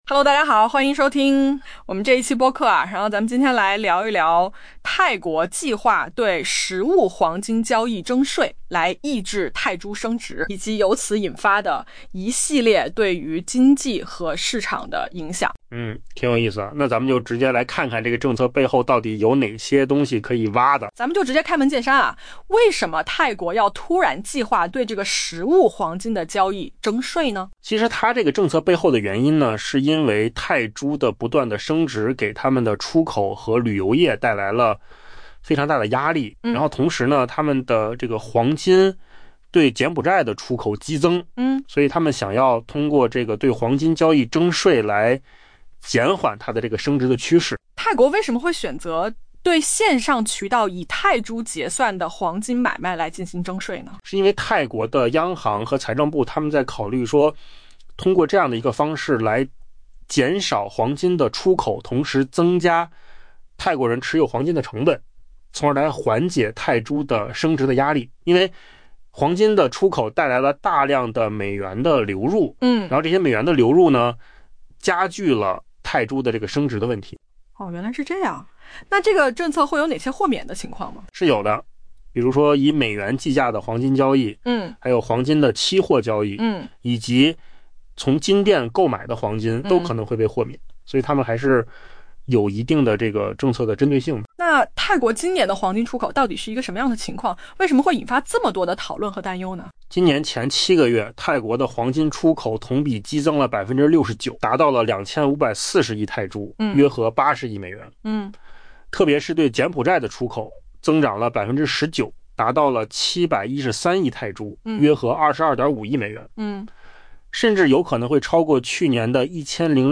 AI 播客：换个方式听新闻 下载 mp3 音频由扣子空间生成 据外媒报道， 泰国当局正考虑对实物黄金交易征税。